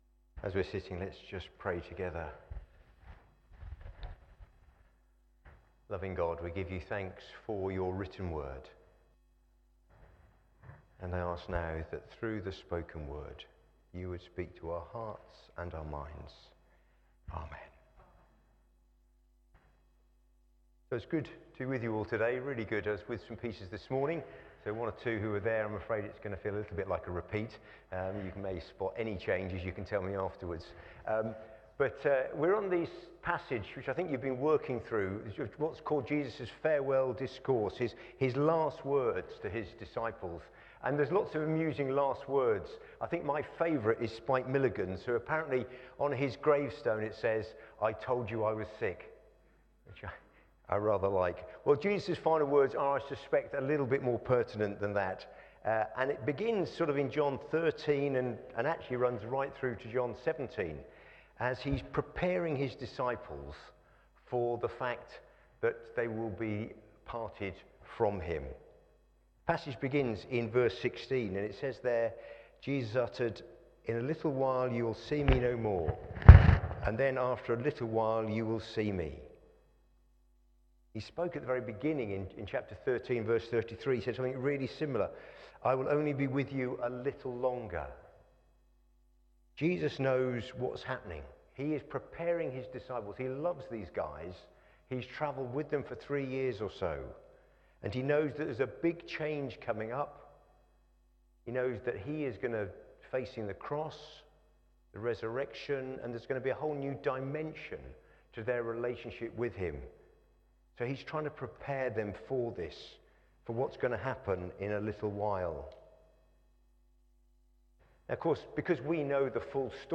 Media Library The Sunday Sermons are generally recorded each week at St Mark's Community Church.
Series: In the Upper Room Theme: Joy will follow grief Sermon